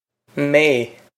mé may
Pronunciation for how to say
This is an approximate phonetic pronunciation of the phrase.